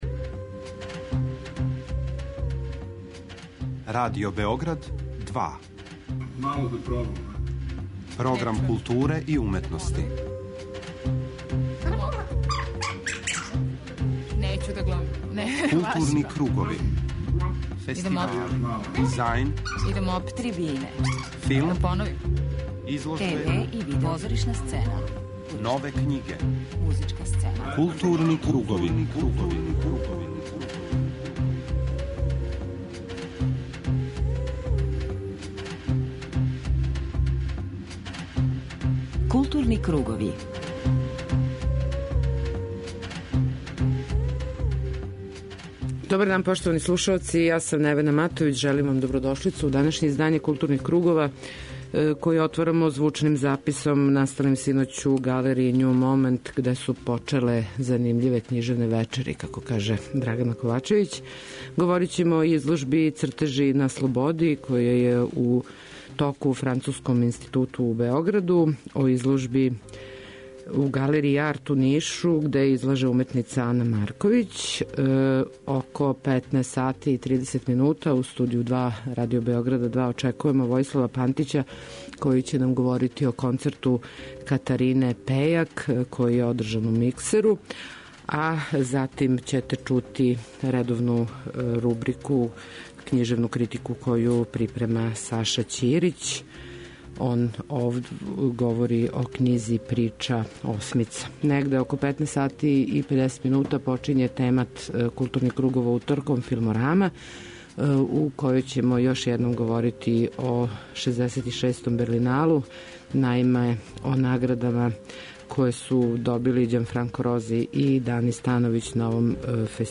И данас у Филморами, захваљујући љубазности прес службе Берлинала, чућете како је протекла завршна фестивалска церемонија и шта су поводом својих награда изјавили неки од награђених аутора.